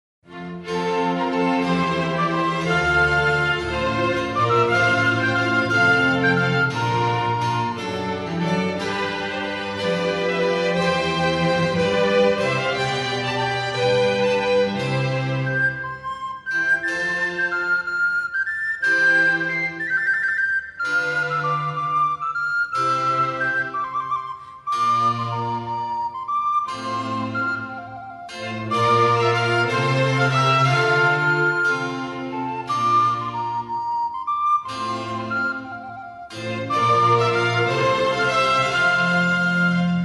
Telemann Suita pro zobcovou flétnu a orchestr, gigue 0:40 481 KB